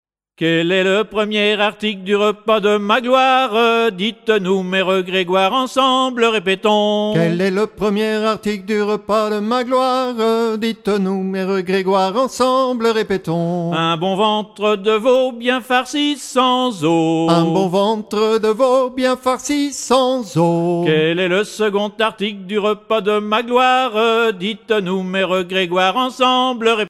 Genre énumérative